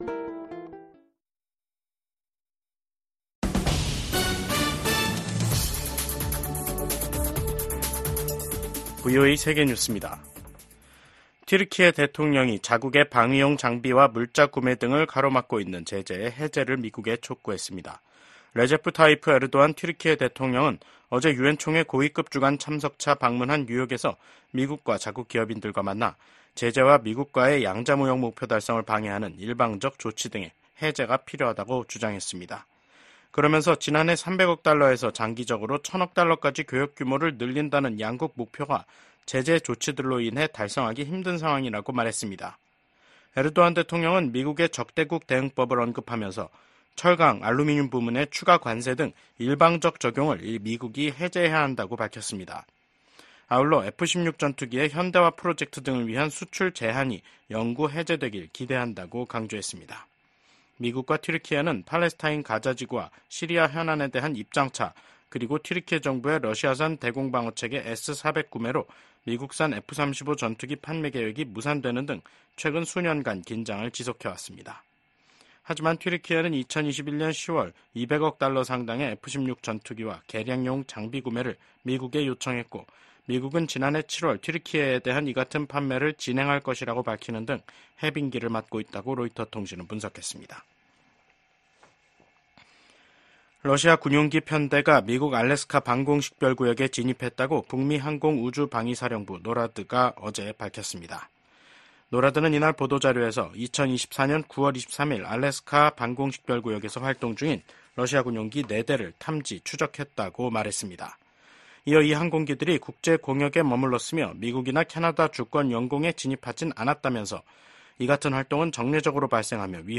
VOA 한국어 간판 뉴스 프로그램 '뉴스 투데이', 2024년 9월 24일 2부 방송입니다. 미한일 3국이 외교장관 회의를 개최하고 ‘정치적 전환기’ 속 변함 없는 공조 의지를 확인했습니다. 미국 정부는 북한의 7차 핵실험이 정치적 결정만 남은 것으로 평가한다고 밝혔습니다.